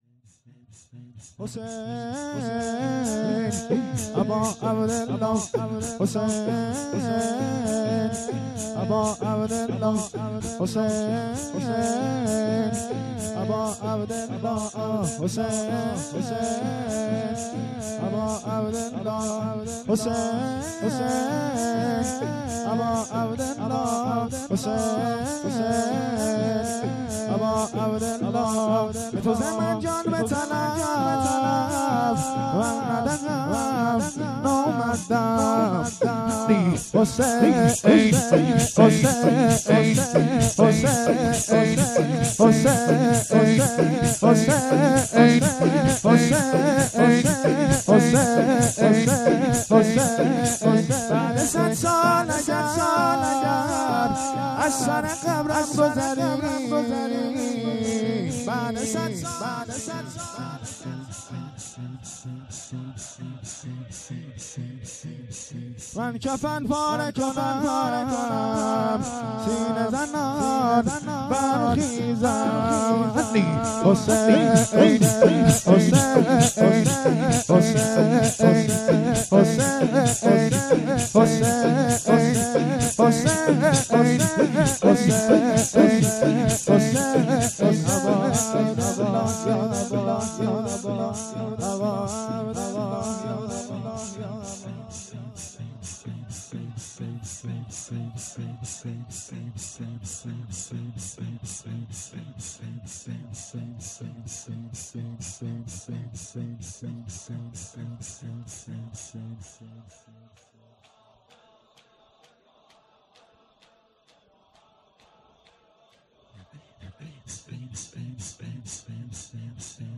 شور
مراسم شهادت امام جواد علیه السلام ۱۴۰۴